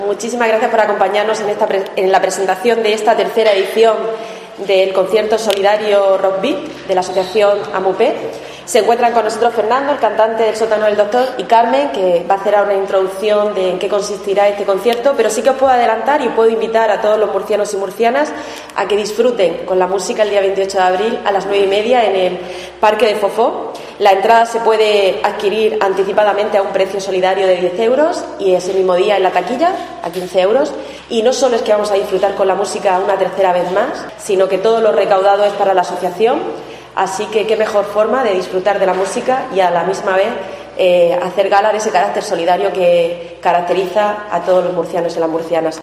Paqui Pérez, concejala de Mayores, Vivienda y Servicios Sociales